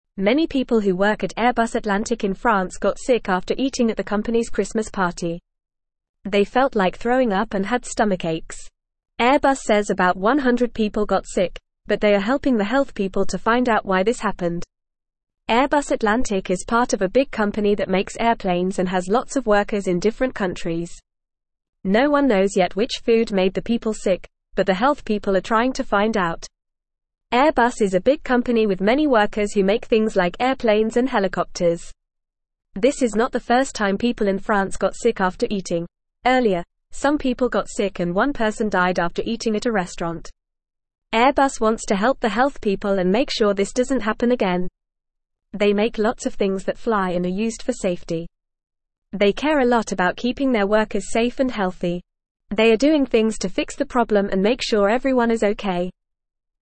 Fast
English-Newsroom-Lower-Intermediate-FAST-Reading-Sick-Workers-at-Airplane-Companys-Christmas-Party.mp3